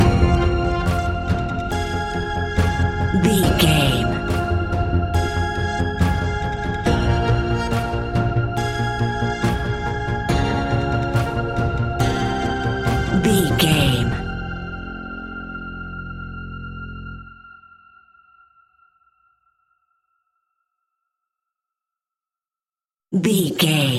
In-crescendo
Thriller
Aeolian/Minor
ominous
dark
eerie
synthesizer
horror music
Horror Synths